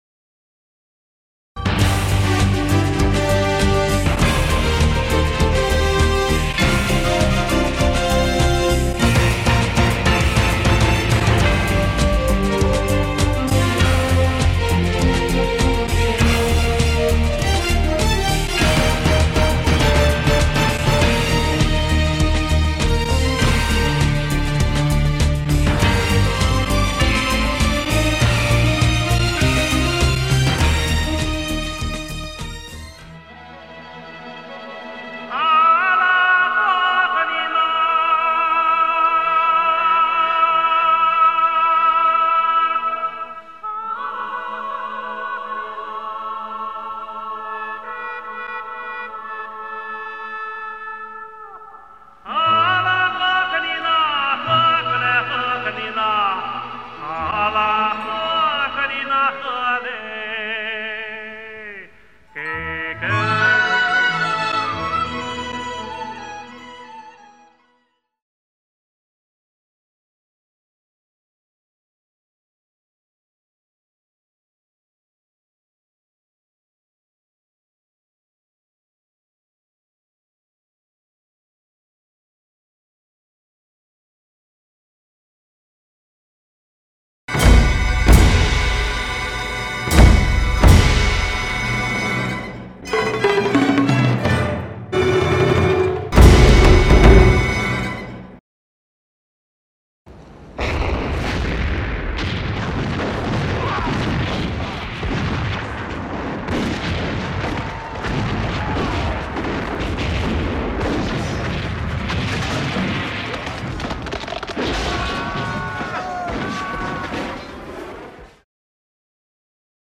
hwhs_no_narration.mp3